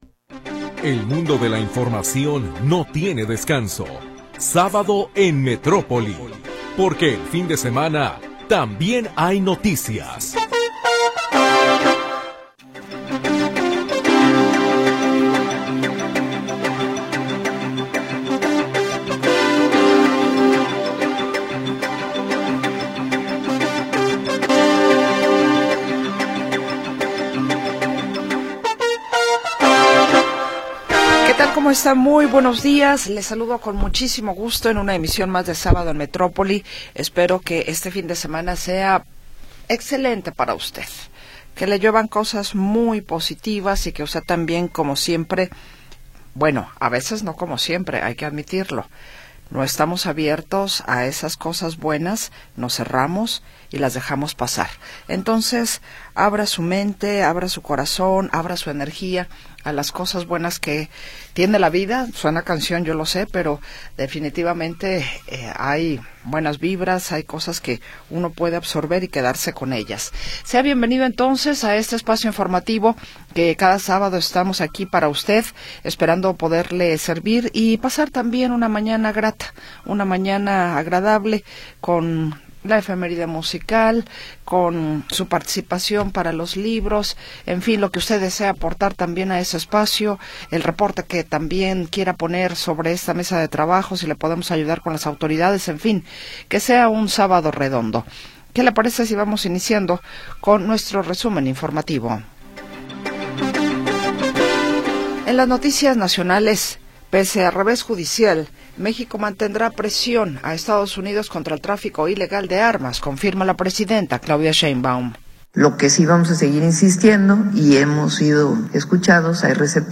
Primera hora del programa transmitido el 7 de Junio de 2025.